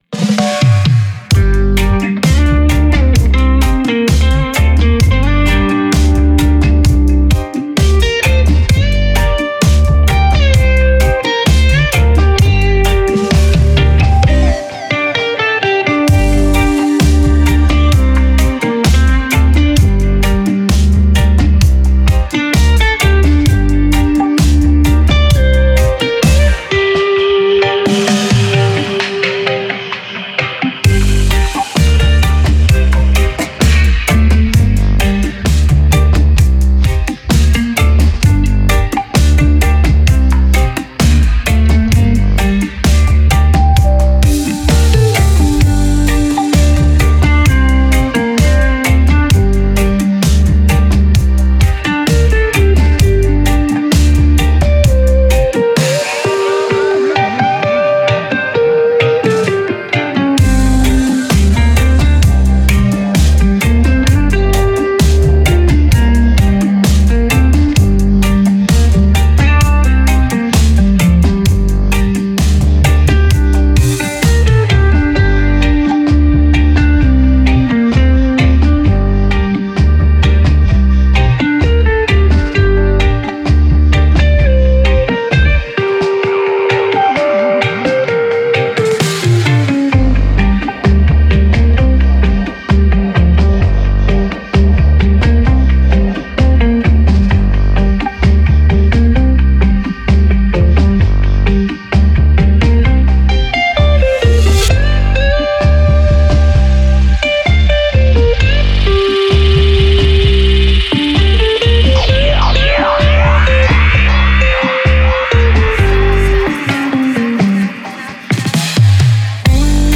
yes there's snippets of the original in there